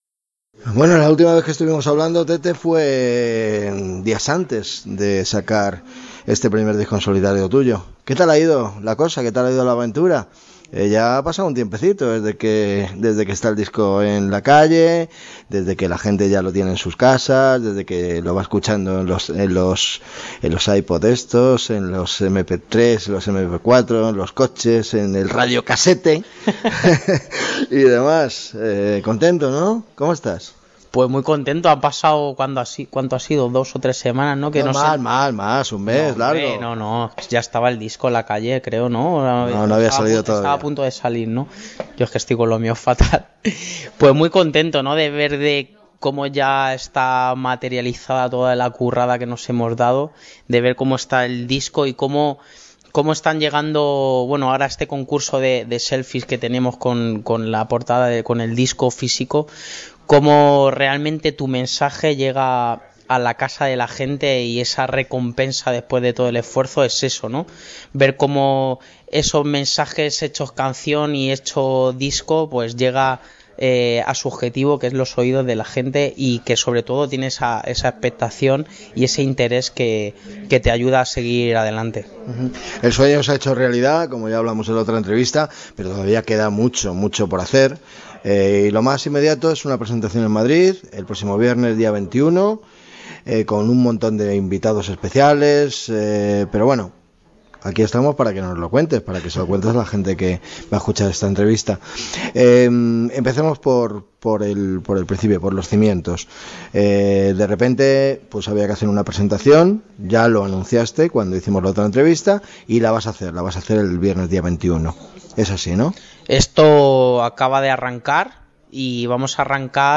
Entrevista
Esta es la entrevista.